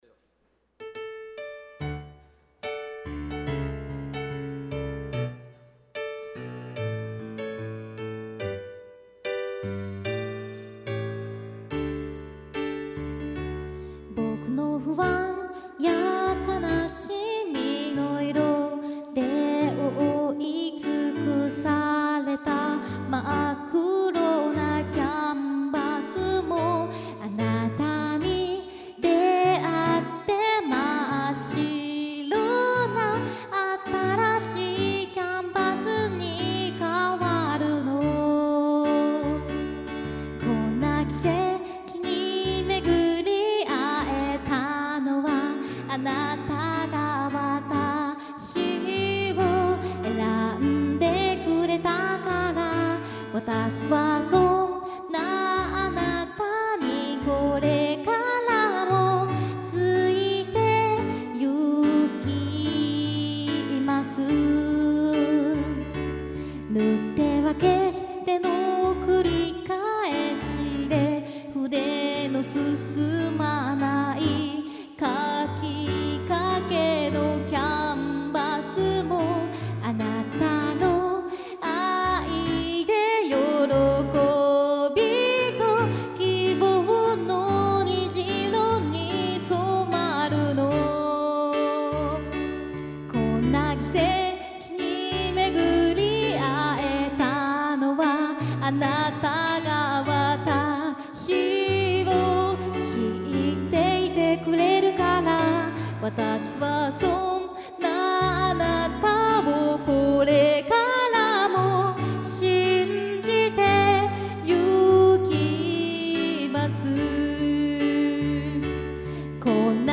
オリジナルゴスペル